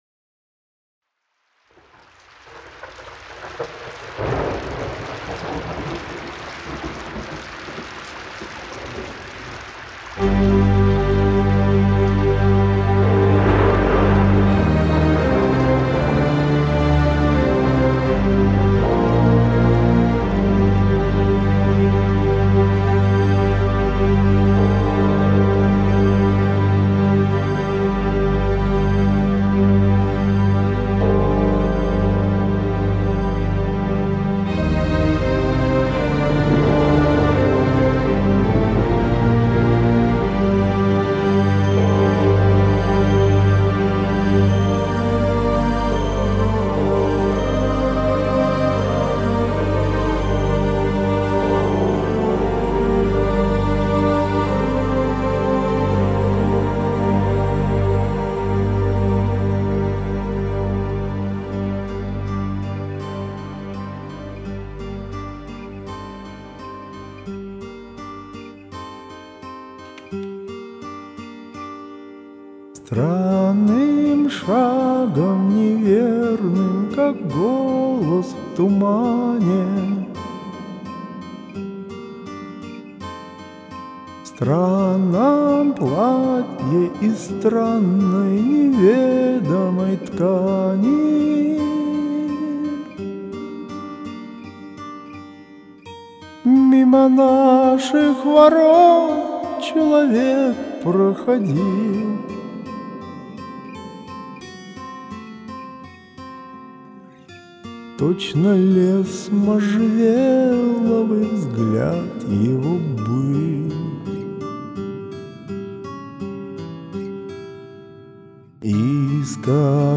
Сведение обоих вещей мое.
Рок-баллада , очень сложный вокальный материал.